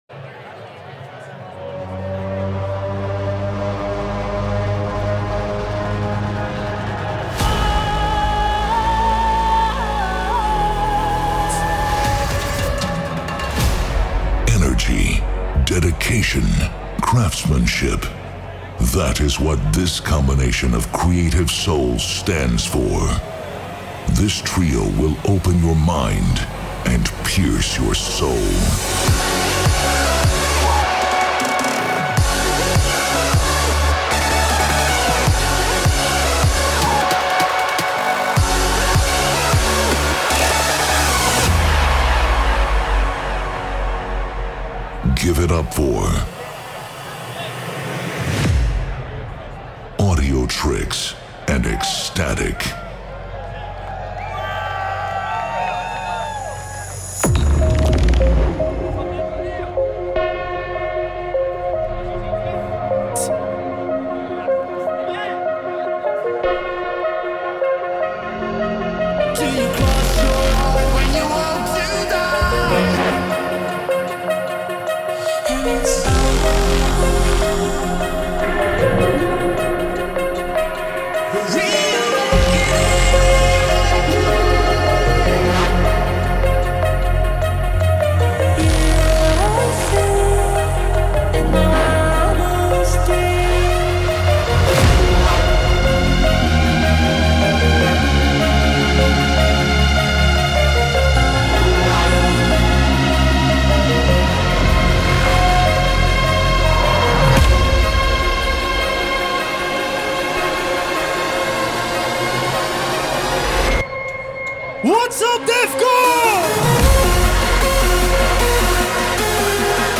Also find other EDM Livesets, DJ Mixes and Radio
The liveset